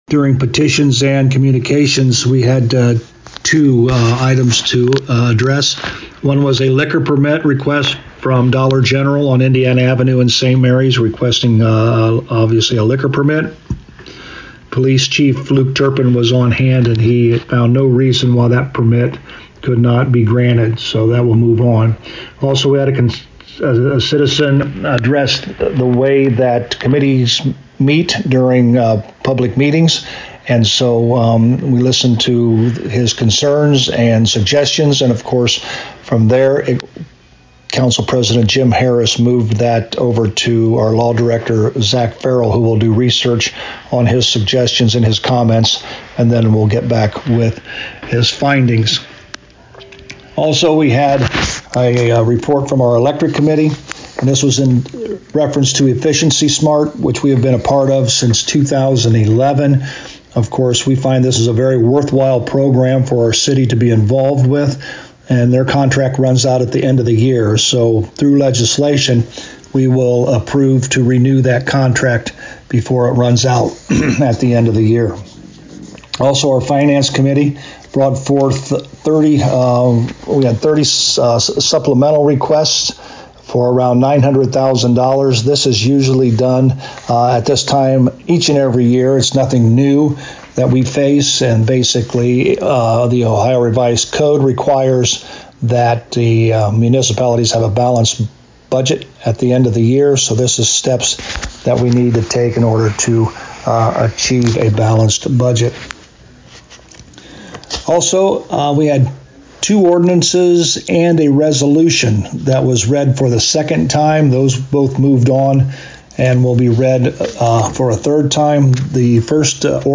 To hear the recap with Mayor Joe Hurlburt: